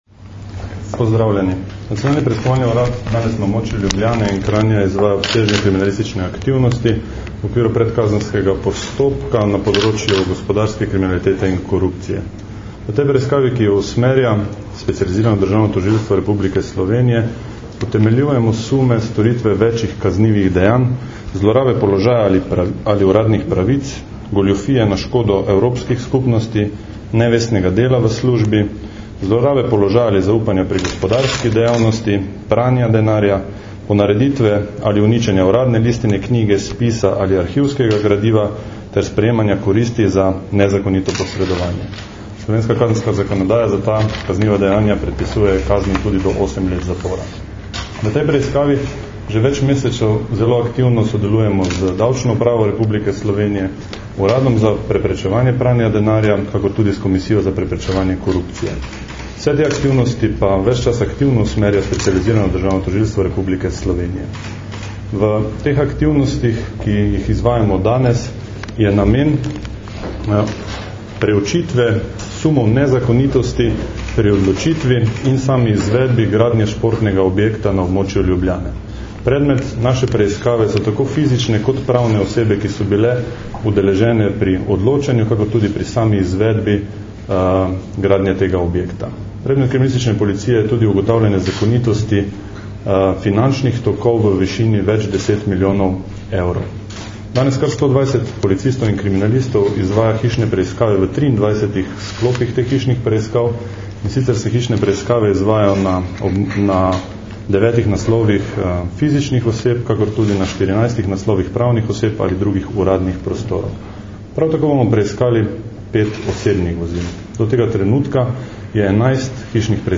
Več je o zelo intenzivni, nekajmesečni preiskavi povedal Robert Črepinko, pomočnik direktorja Nacionalnega preiskovalnega urada, ki po pooblastilu generalnega direktorja policije vodi NPU.
Zvočni posnetek izjave Roberta Črepinka (mp3)